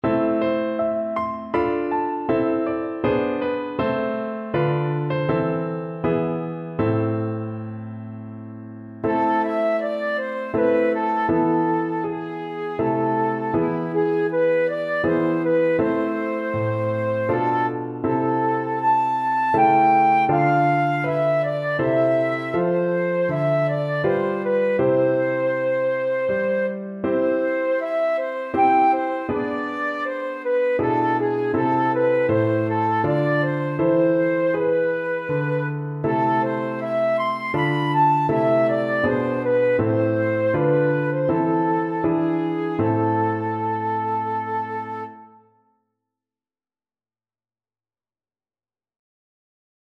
Flute
A minor (Sounding Pitch) (View more A minor Music for Flute )
3/4 (View more 3/4 Music)
Andante
E5-C7
Traditional (View more Traditional Flute Music)